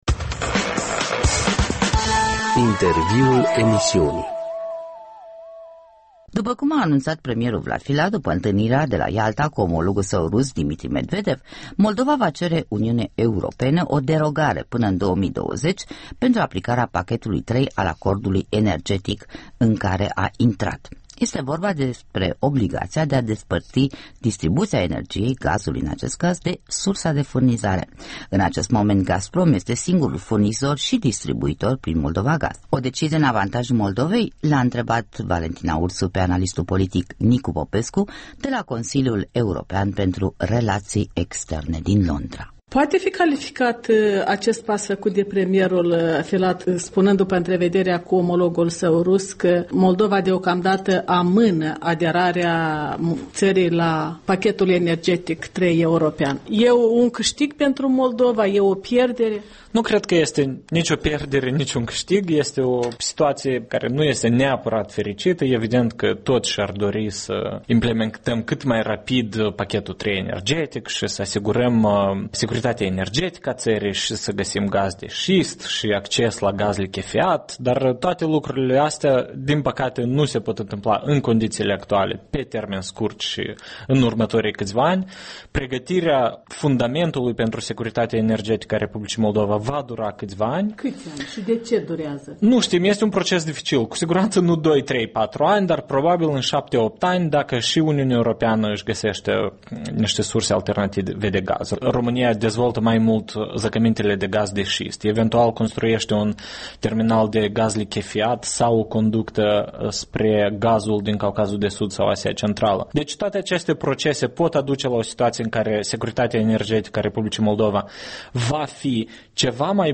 Un interviu cu analistul politic Nicu Popescu